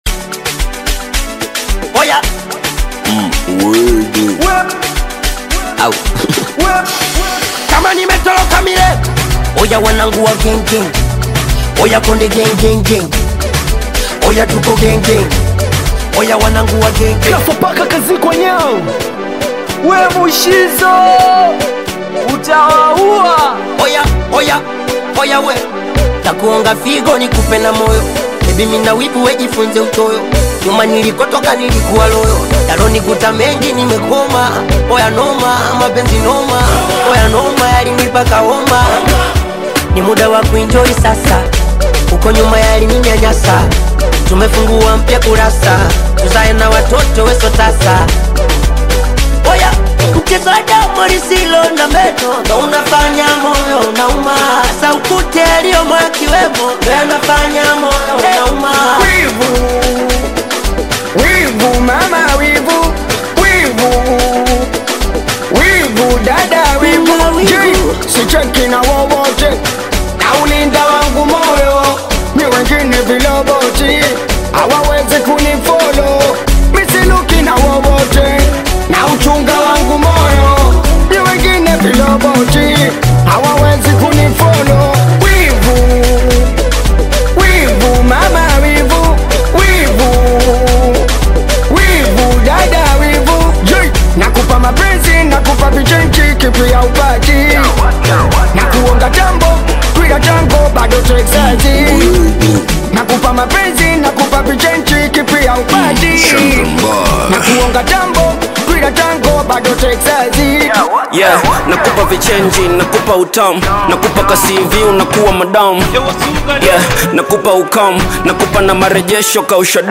Tanzanian Bongo Flava
Singeli You may also like